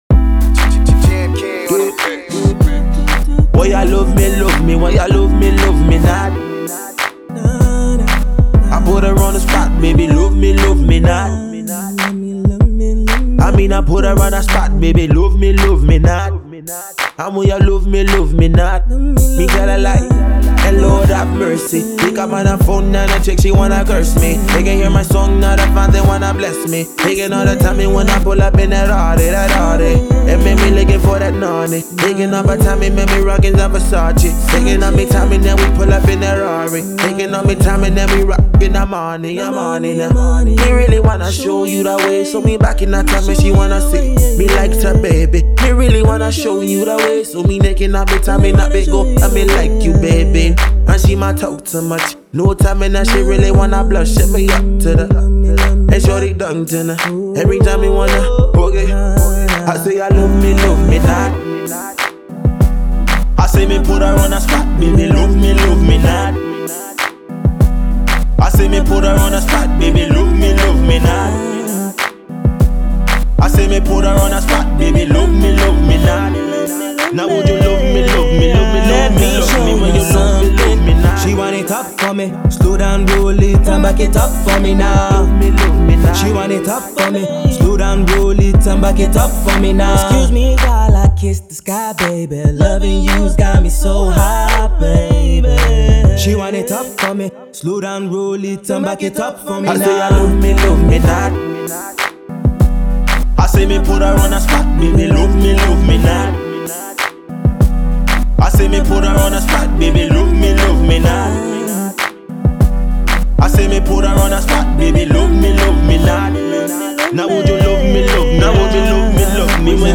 fluid vocals
is a massive, solid tune!